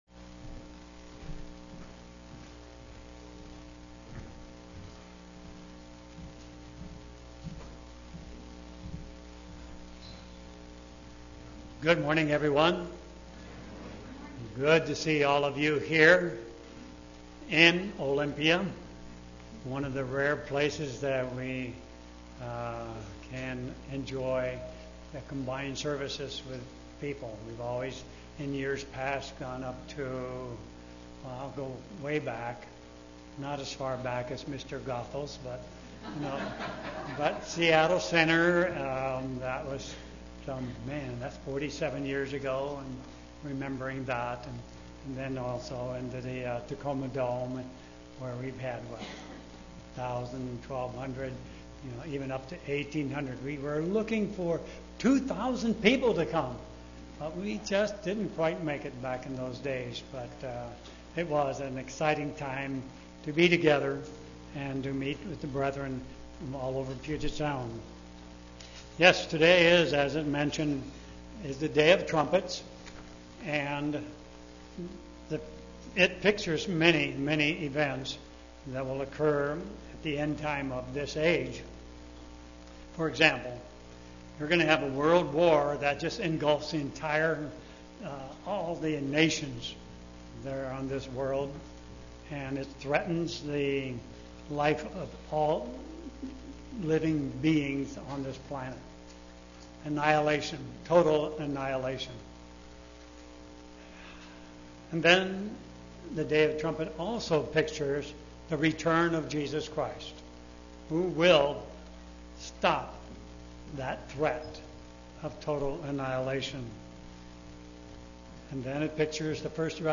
Sermons
Given in Olympia, WA